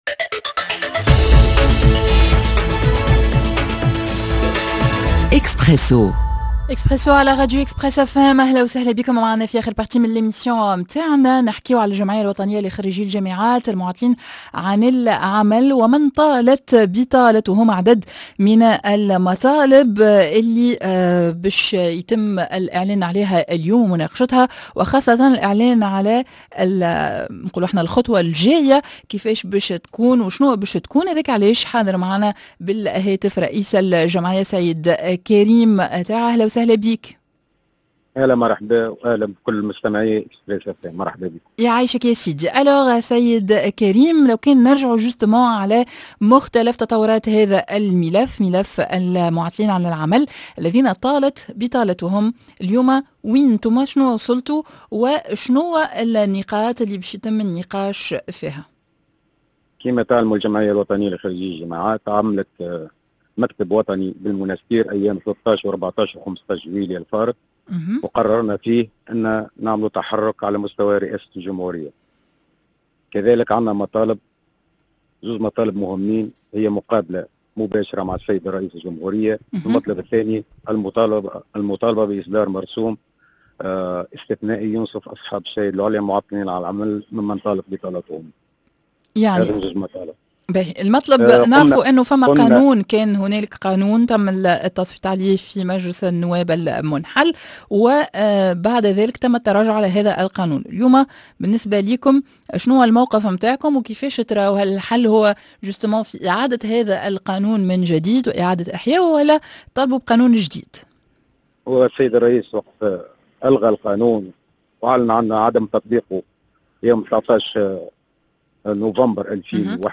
مطالب عدة لخرجي الجامعات العاطلين عن العمل، معانا عبر الهاتف